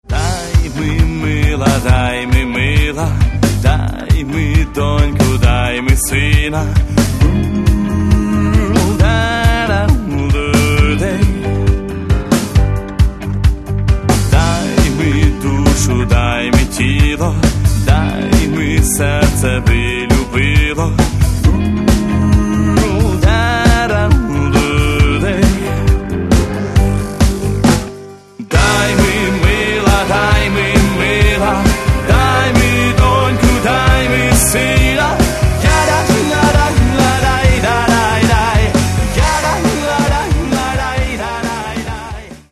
Каталог -> Рок та альтернатива -> Фолк рок